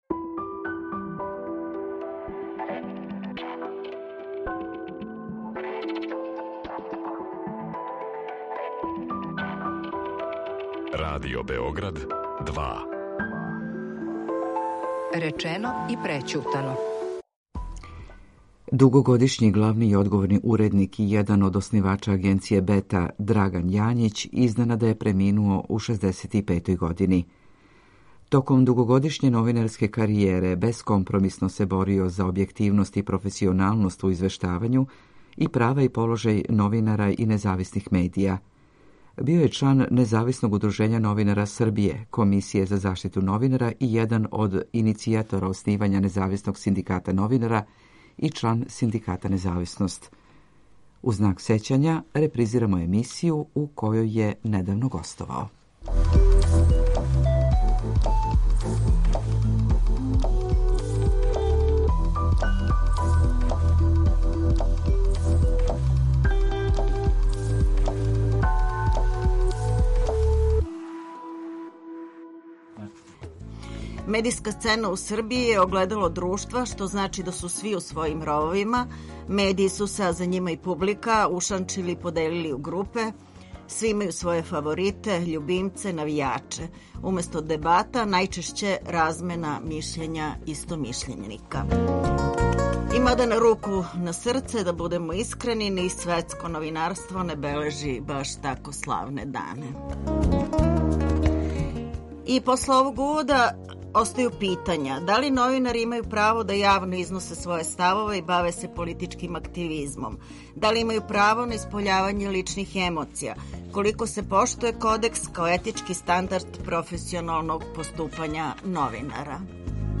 У знак сећања, репризирамо емисију у којој је недавно гостовао.